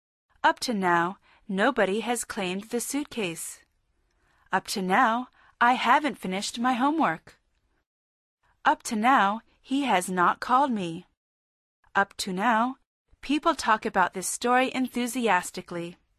Este curso OM TALK de conversación fue desarrollado en inglés americano.